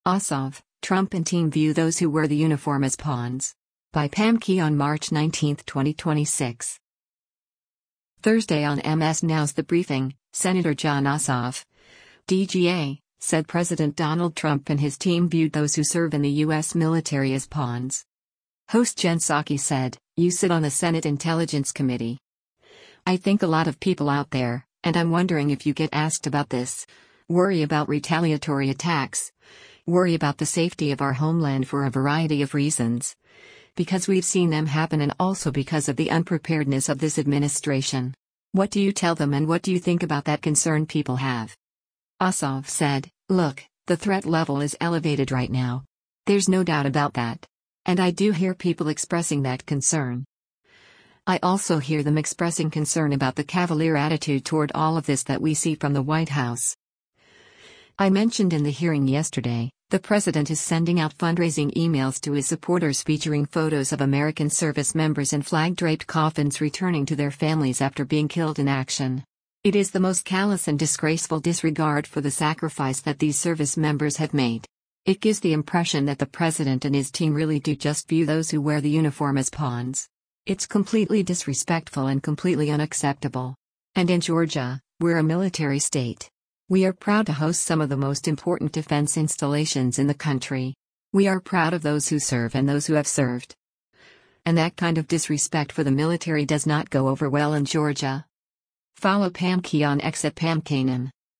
Thursday on MS NOW’s “The Briefing,” Sen. Jon Ossoff (D-GA) said President Donald Trump and his team viewed those who serve in the U.S. military as “pawns.”